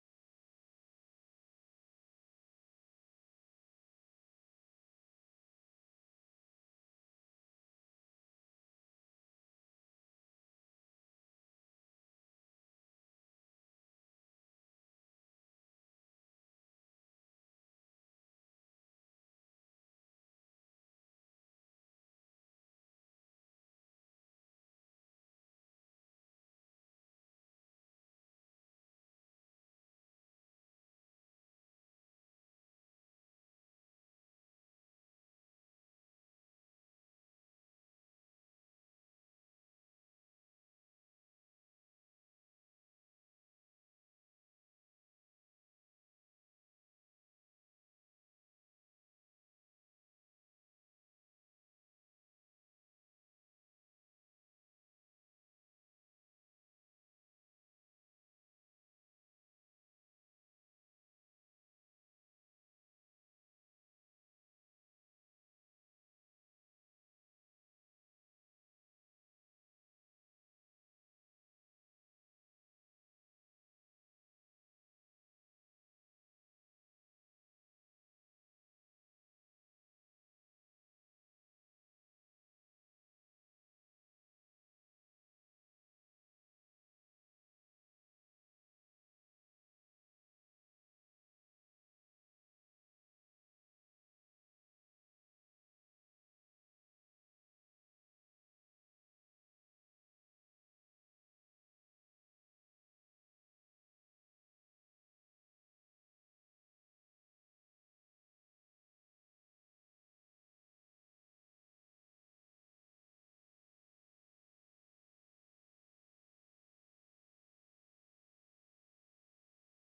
12-10-23 Sunday School Lesson | Buffalo Ridge Baptist Church